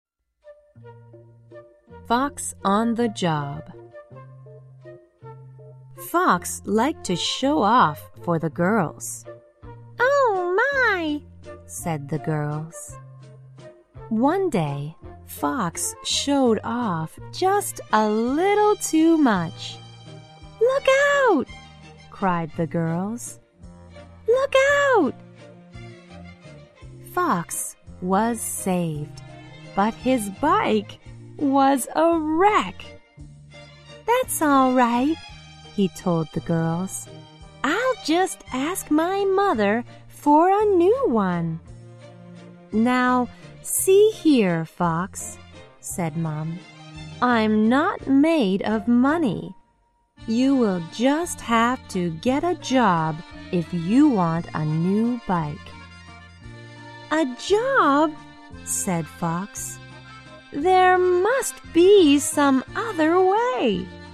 在线英语听力室小狐外传 第74期:小狐打工的听力文件下载,《小狐外传》是双语有声读物下面的子栏目，非常适合英语学习爱好者进行细心品读。故事内容讲述了一个小男生在学校、家庭里的各种角色转换以及生活中的趣事。